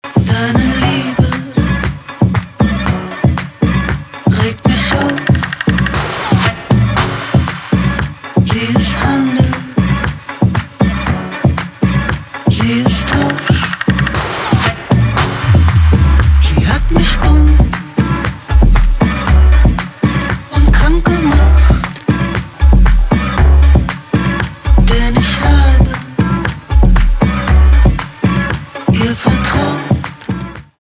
электроника
битовые , басы , кайфовые